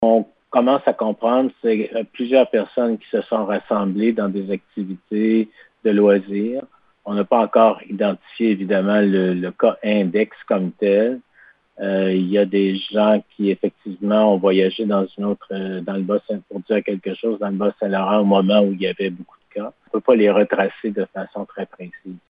Le Dr Yv Bonnier-Viger a fait cette précision mardi en point de presse pour expliquer l’augmentation du nombre de cas sur notre territoire.